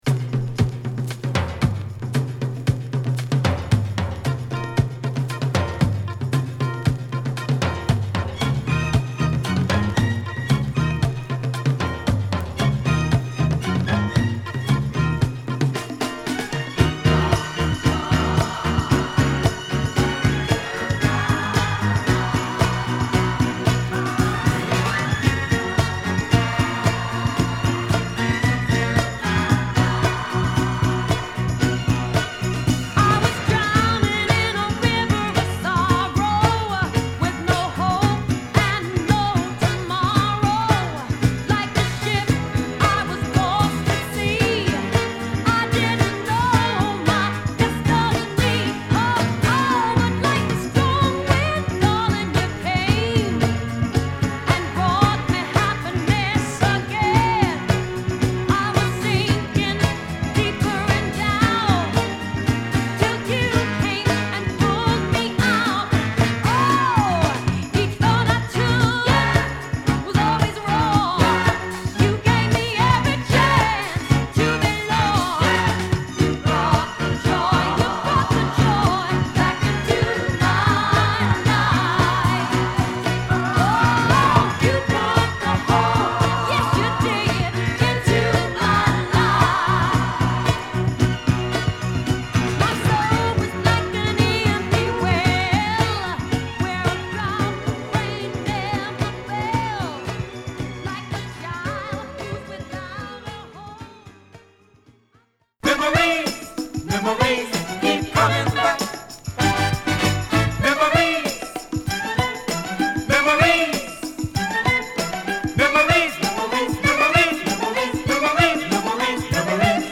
軽やかなリズムに乗ってハツラツと歌う